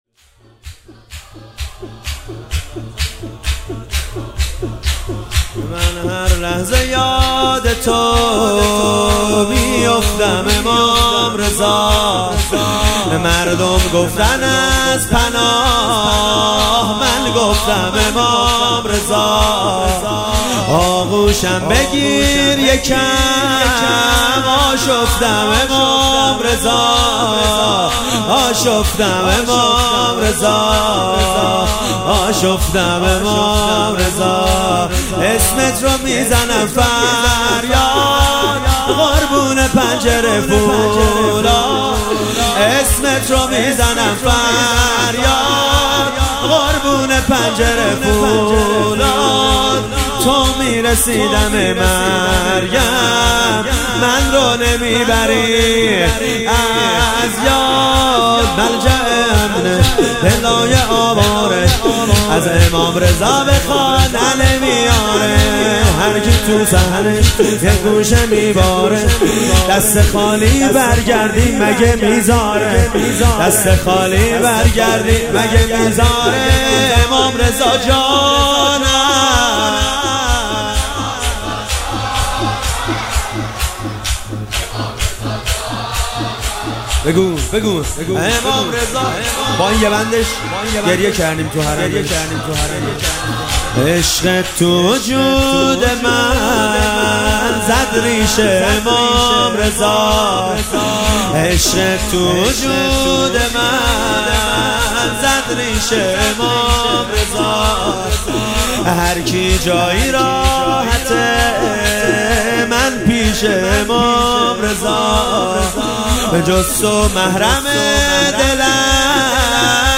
مداحی
مداحی به سبک شور اجرا شده است.